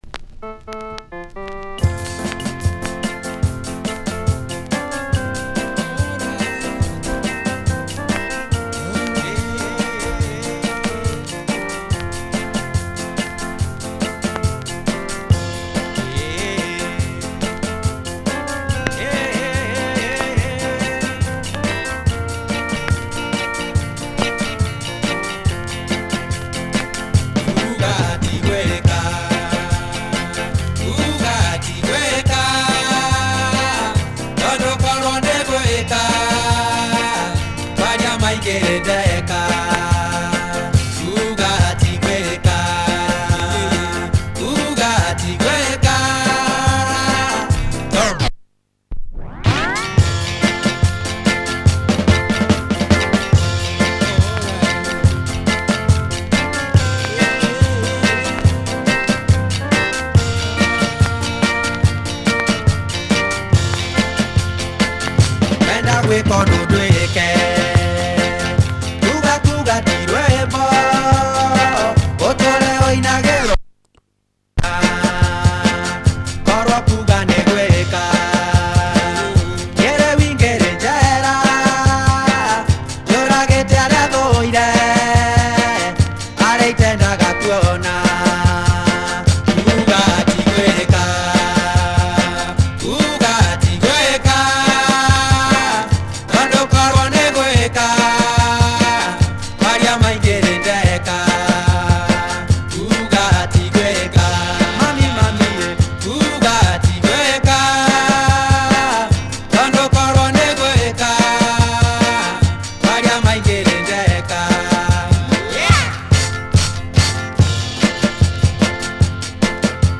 Interesting Kikuyu tune
This one comes out with a steady beat, including kebyoards.